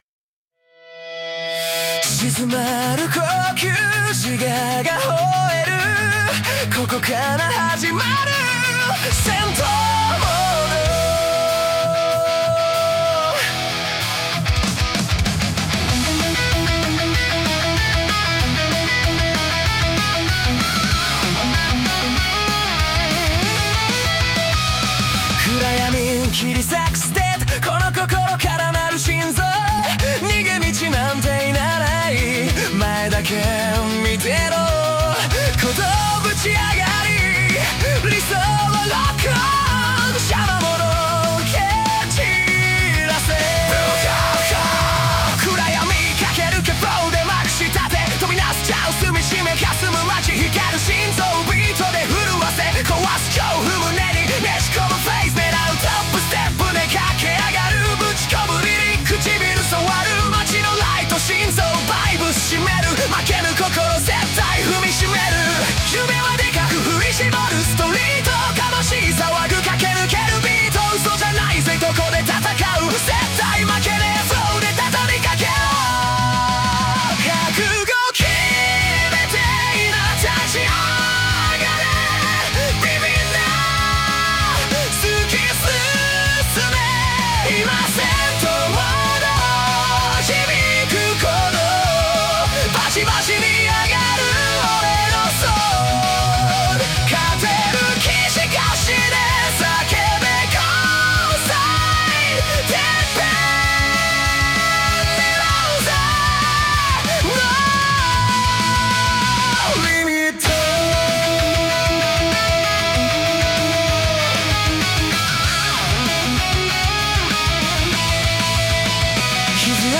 男性ボーカル
イメージ：邦ロック,J-ROCK,ファンク,男性ボーカル,前向き,勇気,応援ソング